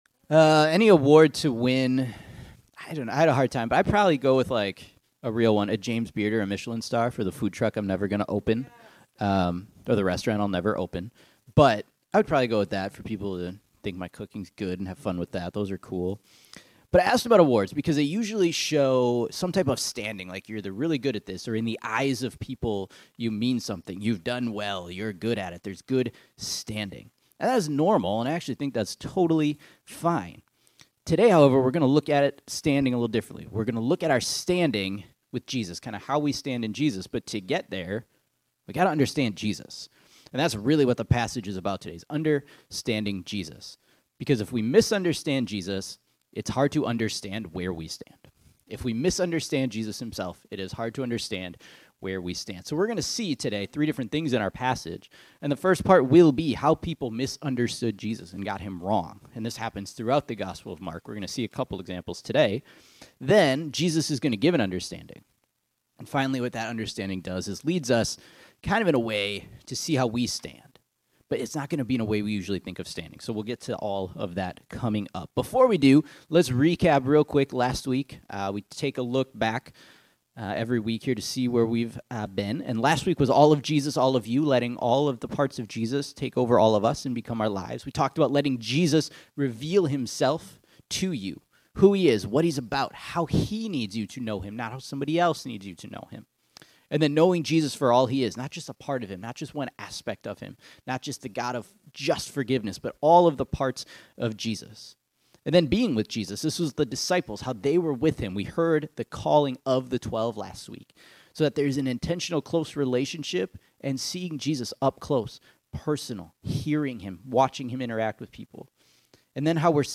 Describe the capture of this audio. Mark Understanding Sunday Morning We continued in Mark 3 and learned about how Jesus was misunderstood.